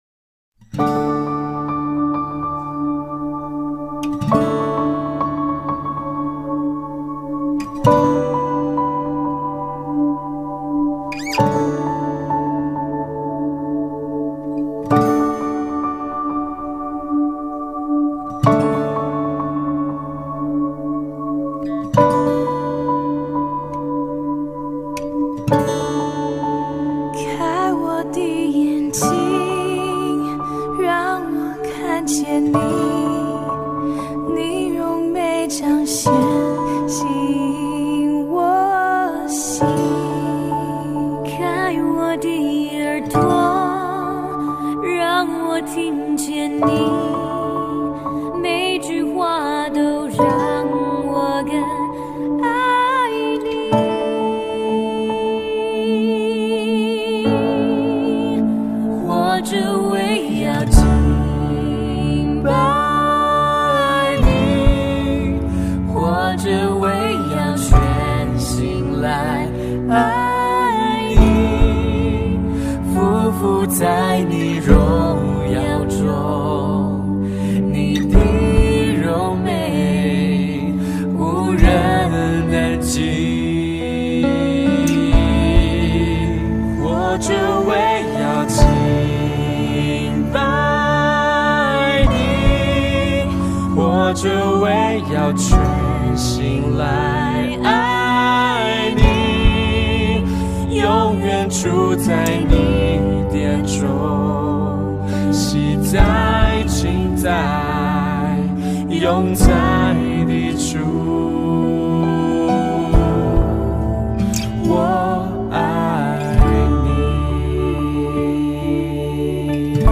主日证道 |  终结的开始